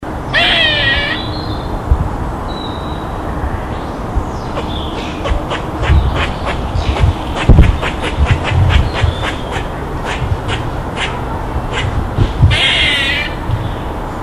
The "chuck" or "quaa" call of a Grey squirrel.
squirrel_chuk.mp3